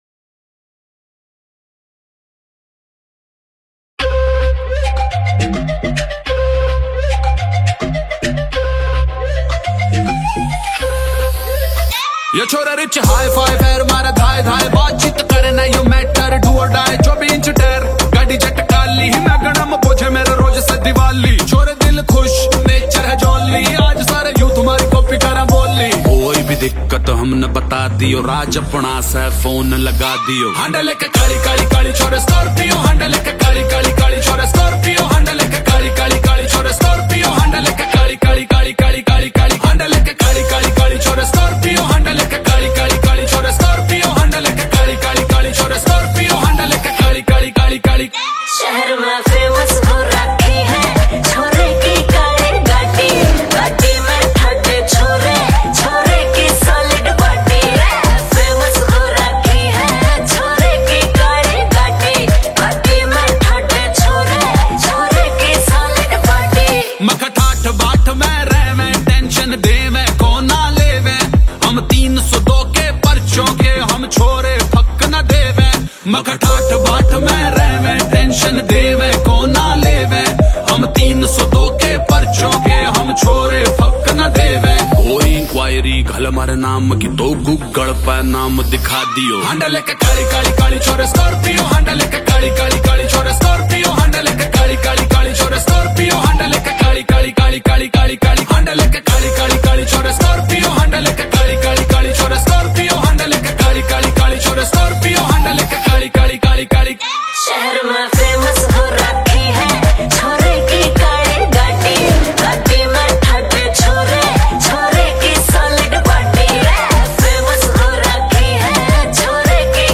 New Haryanvi Mp3 Songs 2025